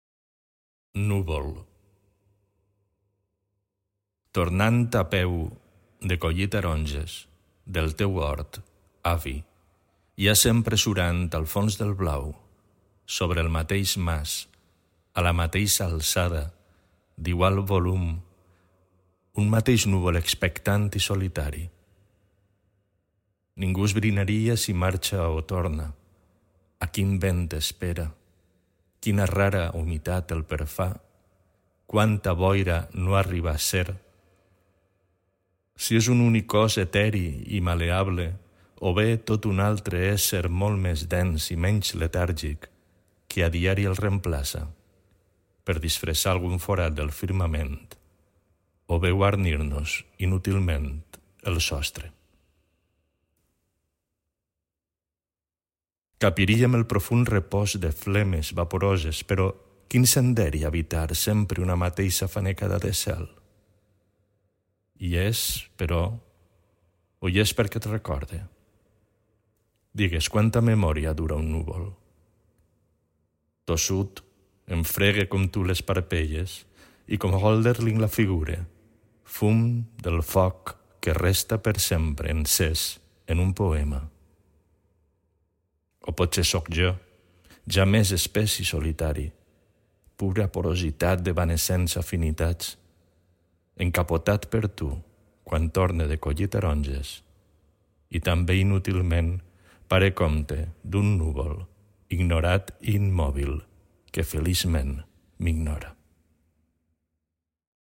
recitació